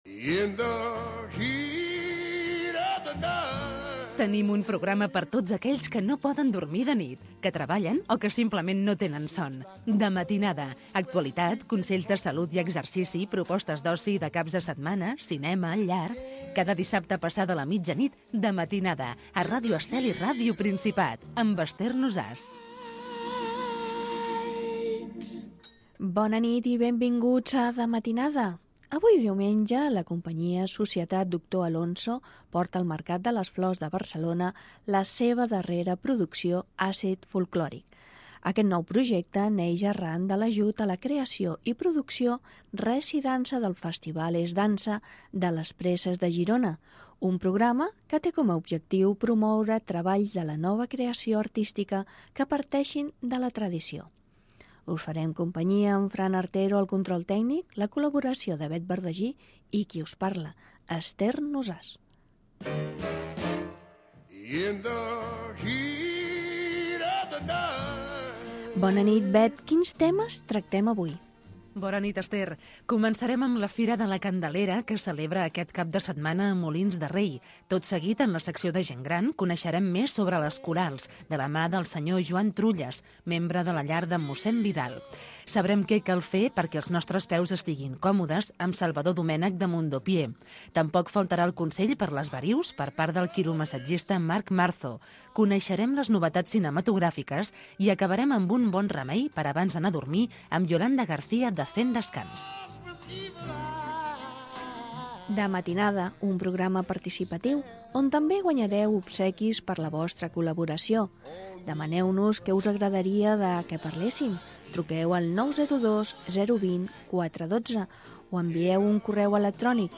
Careta del programa, comentari sobre l'espectacle del Mercat de les Flors de Barcelona
tema musical Gènere radiofònic Entreteniment